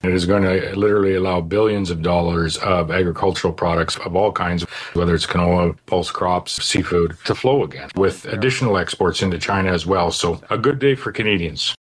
Audio with Canadian Prime Minister Mark Carney, Saskatchewan Premier Scott Moe, and U.S. President Donald Trump.